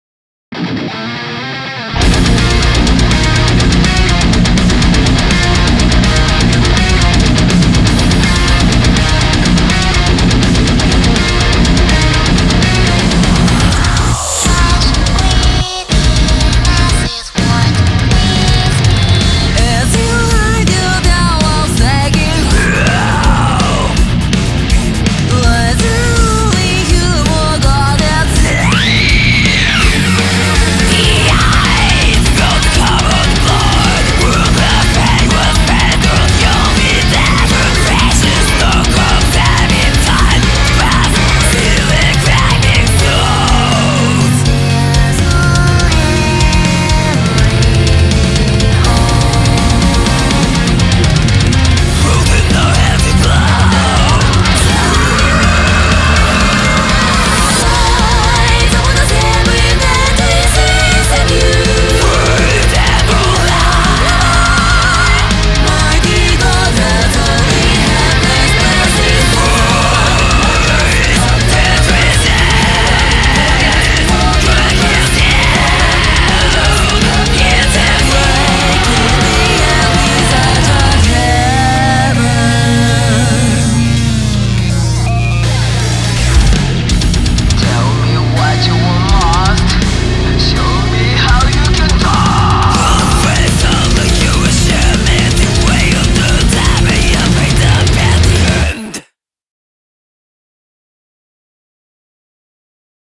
BPM164
Audio QualityPerfect (High Quality)
CommentsYes, I'm charting metal two weeks in a row.